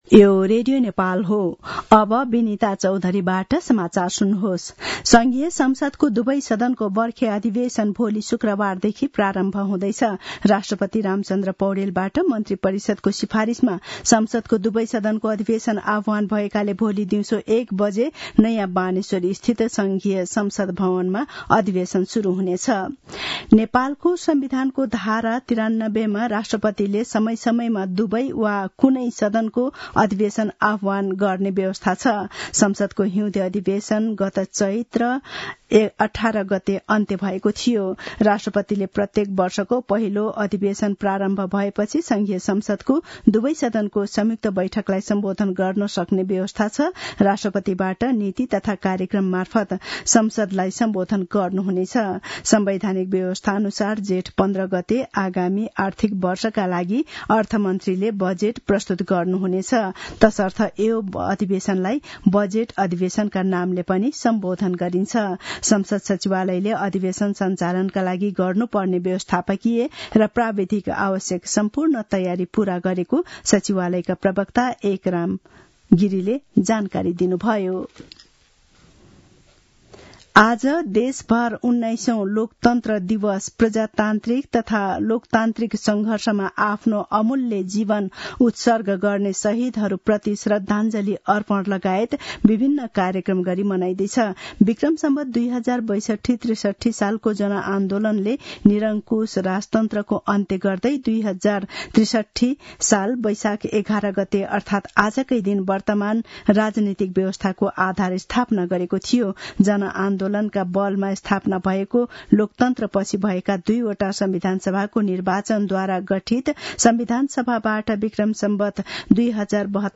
दिउँसो १ बजेको नेपाली समाचार : ११ वैशाख , २०८२